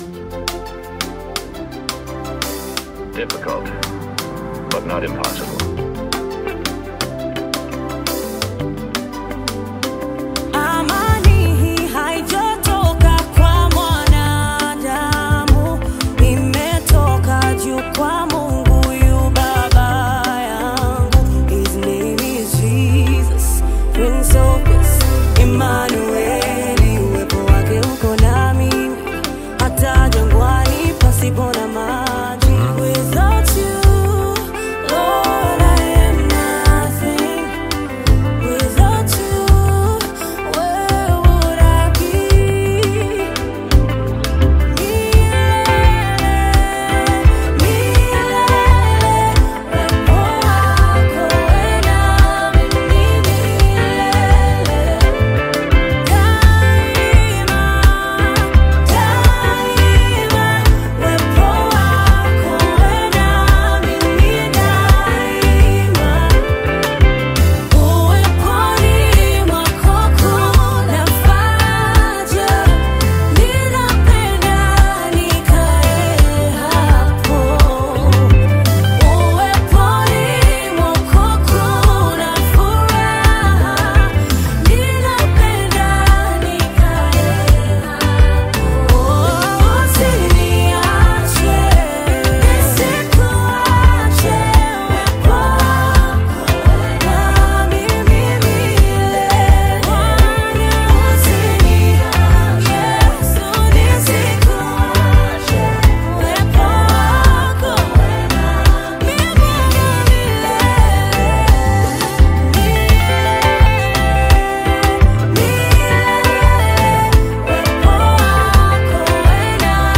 Christian singer-songwriter